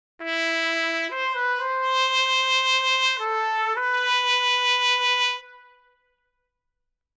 Микрофонные позиции медных
Trumpet Decca.mp3 Trumpet Decca.mp3 280,6 KB · Просмотры: 327 Trumpet Spot.mp3 Trumpet Spot.mp3 280,6 KB · Просмотры: 319 Trumpet.zip Trumpet.zip 3,2 MB · Просмотры: 139